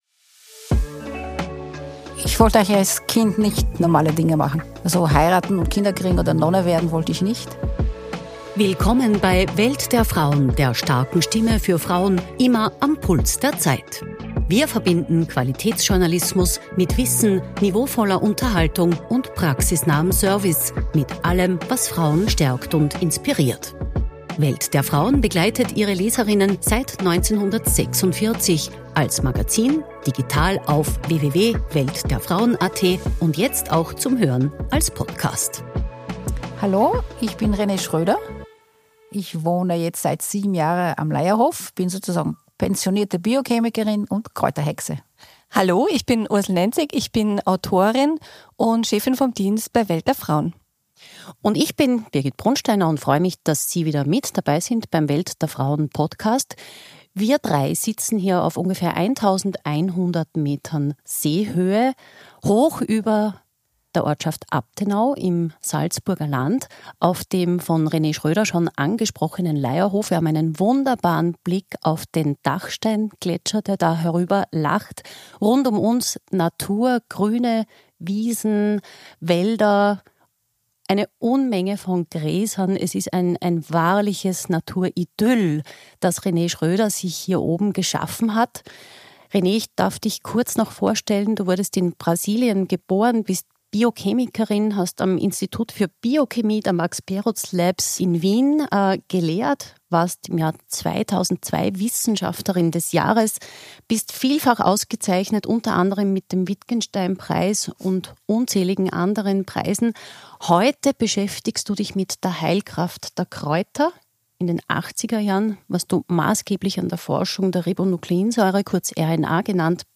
am Leierhof mit Blick auf den Dachsteingletscher